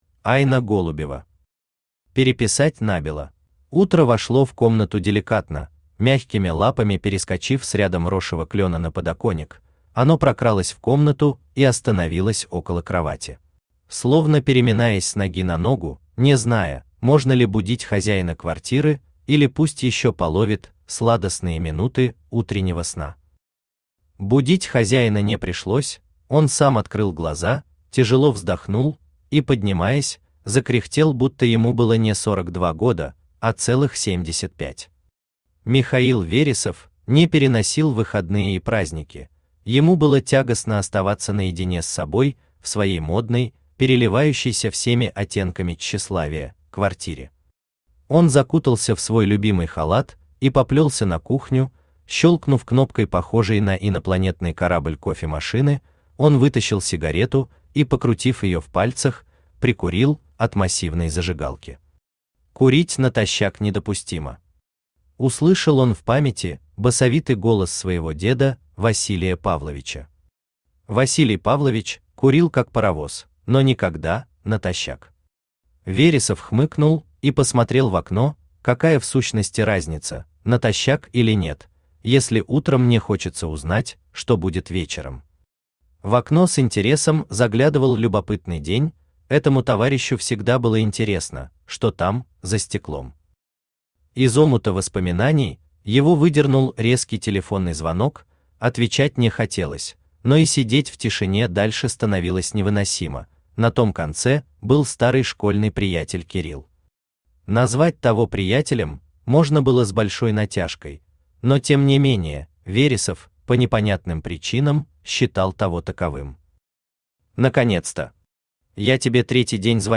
Аудиокнига Переписать набело | Библиотека аудиокниг
Aудиокнига Переписать набело Автор Айна Голубева Читает аудиокнигу Авточтец ЛитРес.